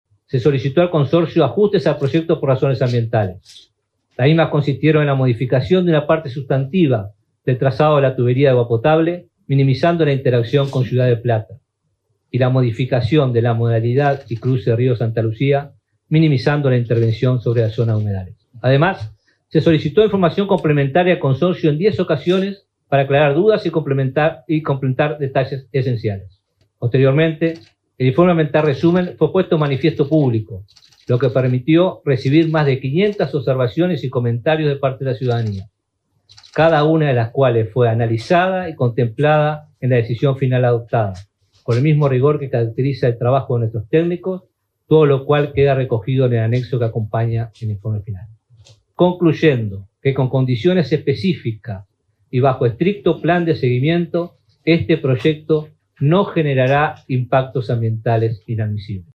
Escuche al ministro de Ambiente Robert Bouvier aquí: